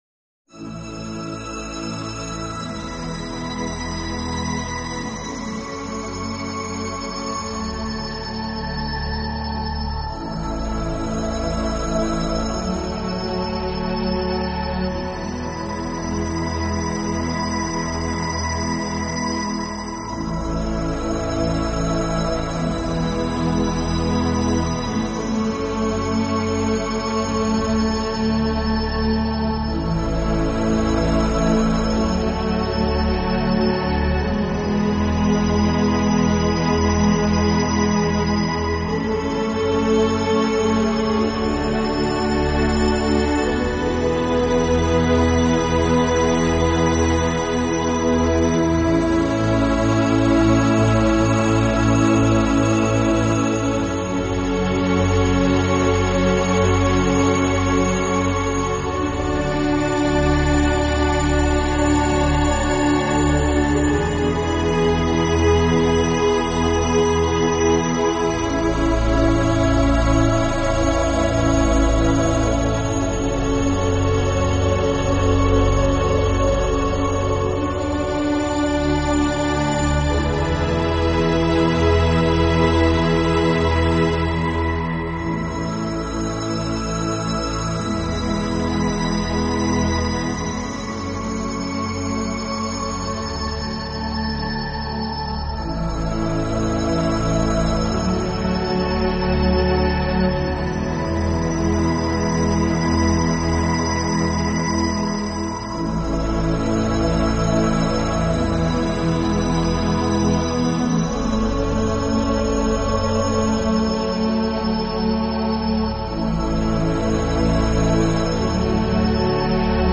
Genre ............. : New Age/Piano